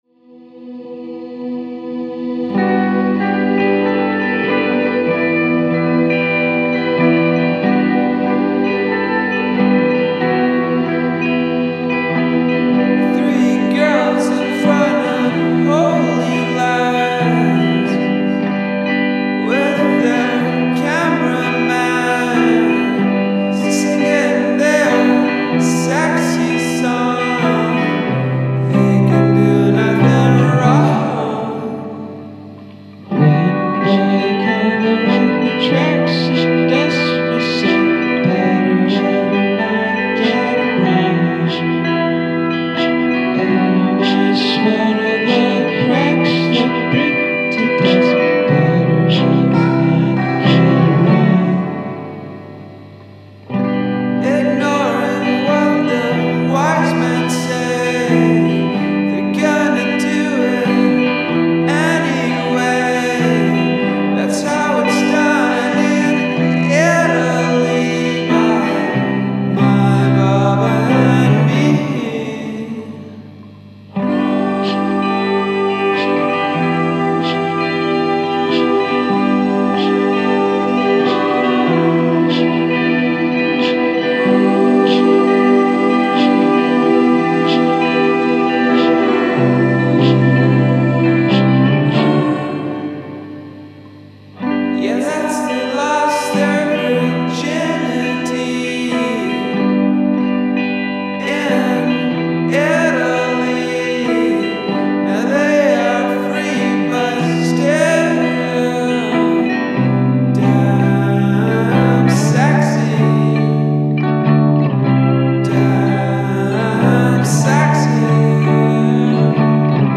super fuzzy lo-fi performer with spacey, hazy vocals